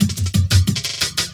17 LOOP08 -R.wav